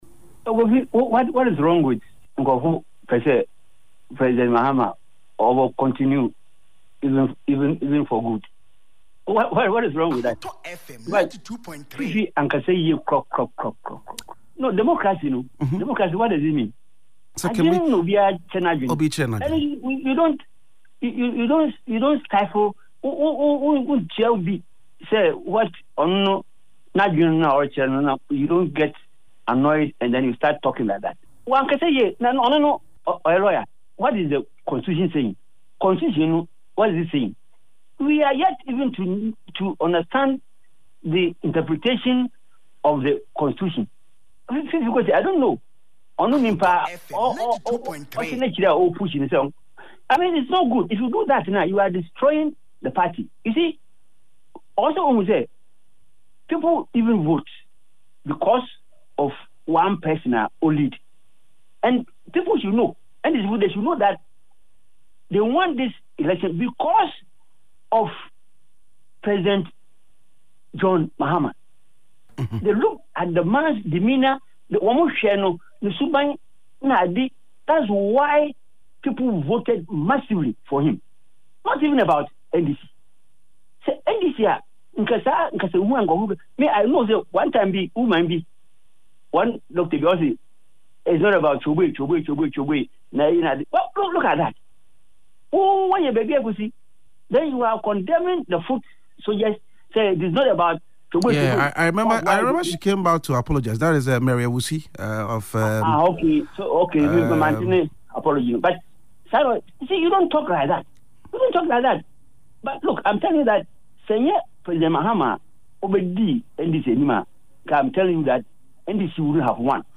Yepe Ahunu, a current affairs programme on Ahotor FM